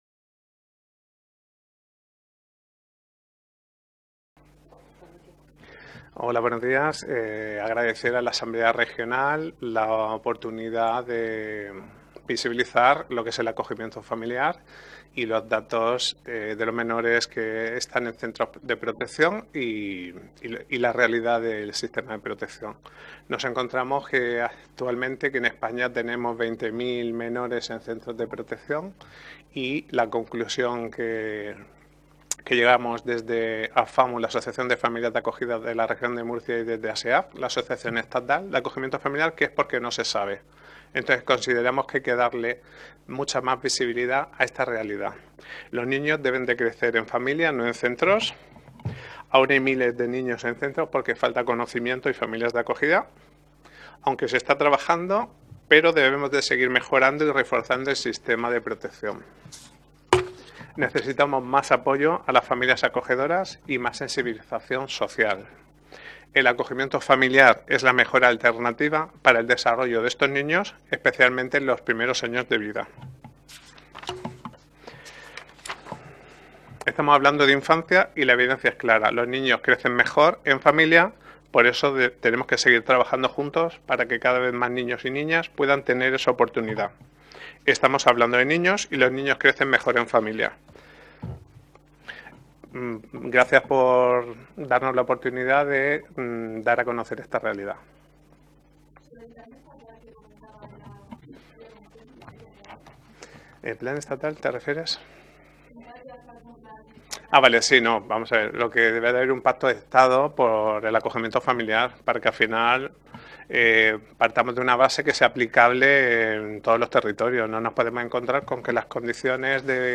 Ruedas de prensa posteriores a la Comisión Especial de Estudio sobre Infancia y Adolescencia